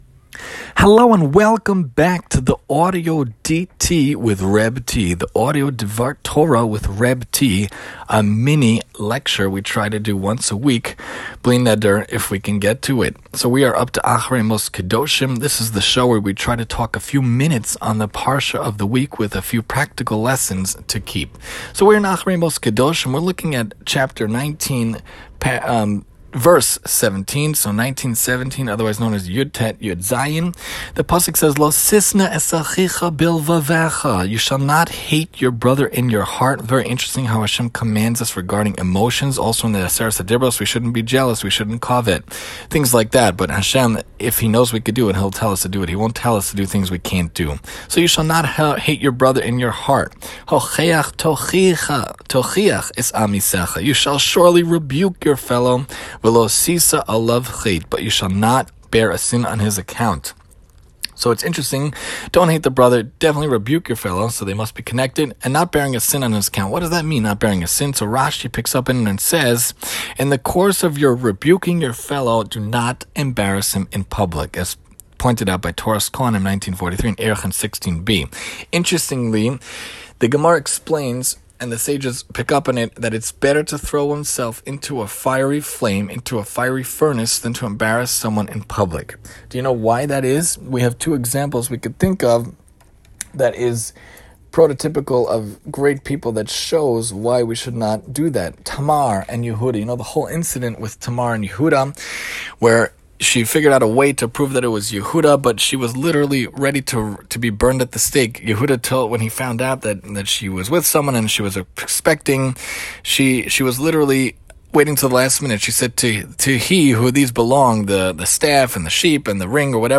Acharei mot-kedoshim Mini-Lecture_